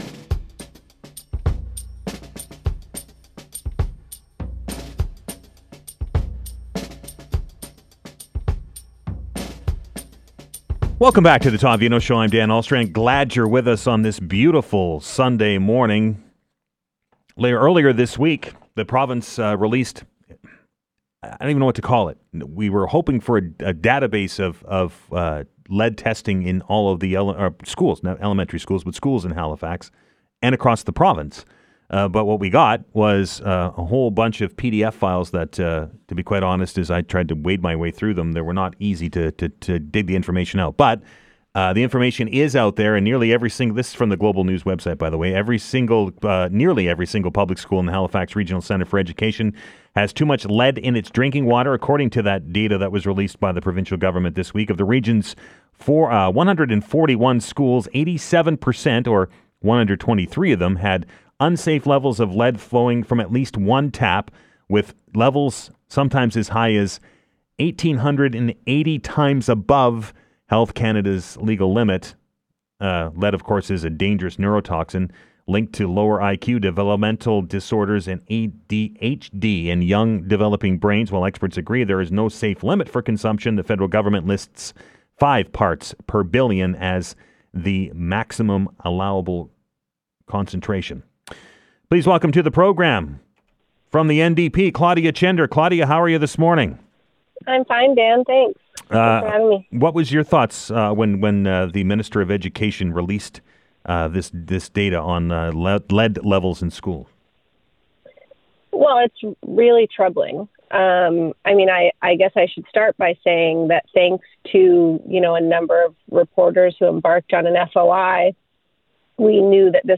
We continue to press for a long-term solution that restores safe and plentiful drinking water to our schools. You can listen to my interview on 95.7